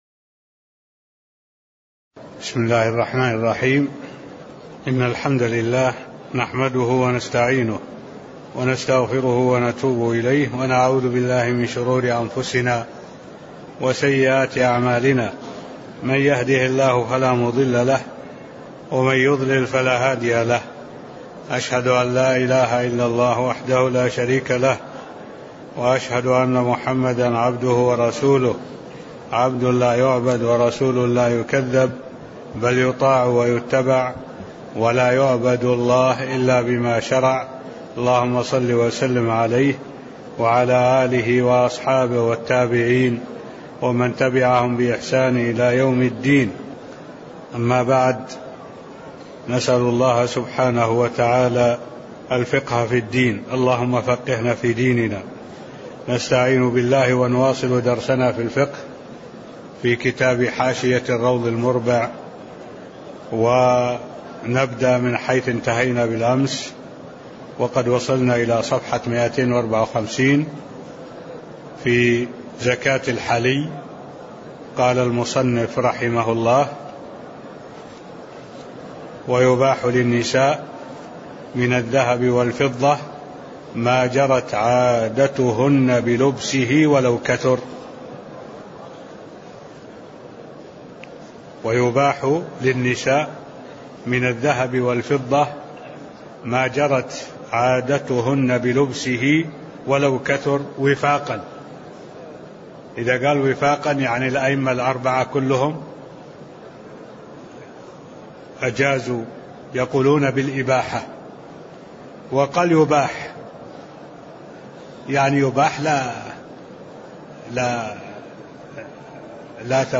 تاريخ النشر ٢٩ ربيع الثاني ١٤٢٩ هـ المكان: المسجد النبوي الشيخ: معالي الشيخ الدكتور صالح بن عبد الله العبود معالي الشيخ الدكتور صالح بن عبد الله العبود زكاة الحلي (007)